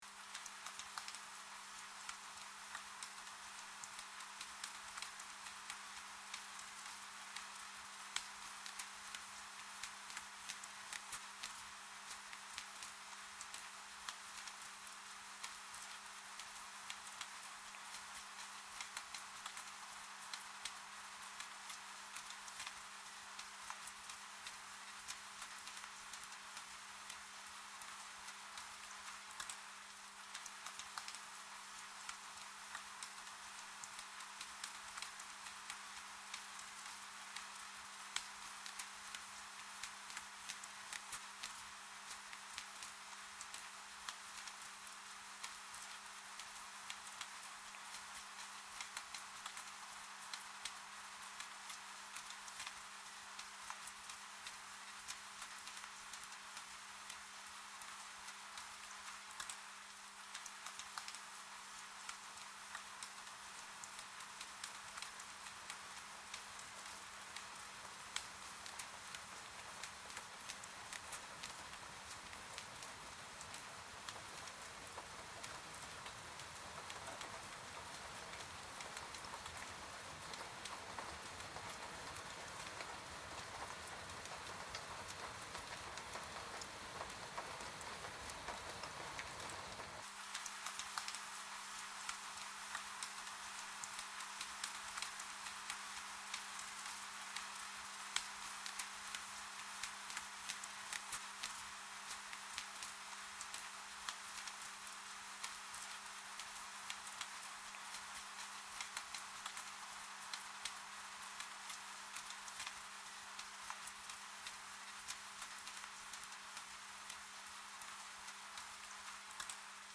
rain.mp3